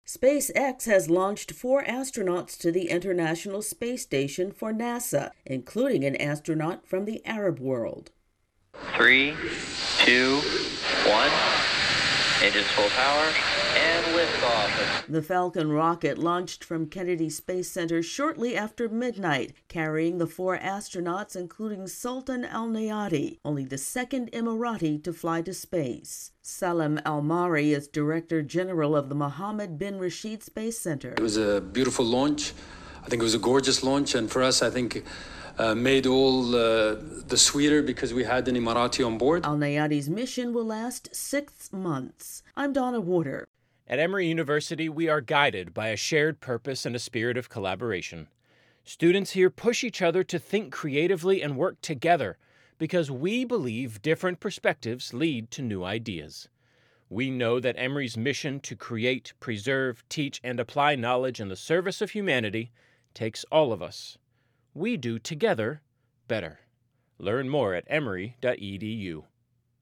correspondent